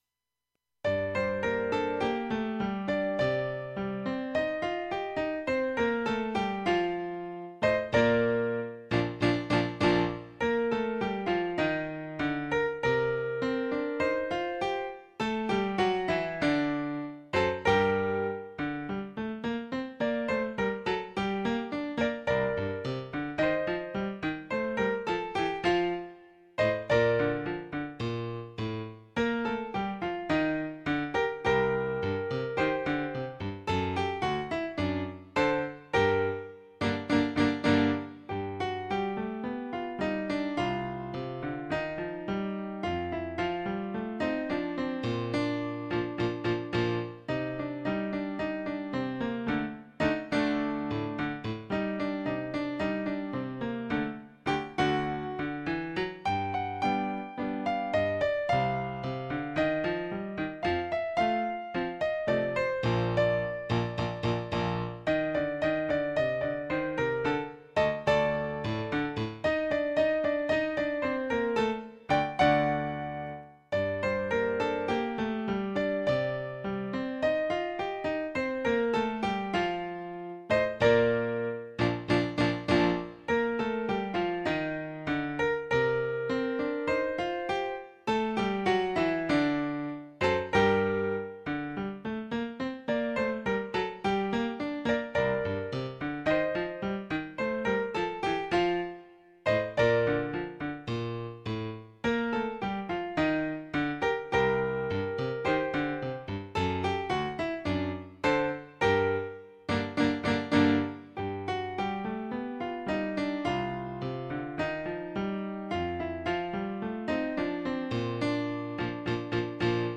Cantante / Singer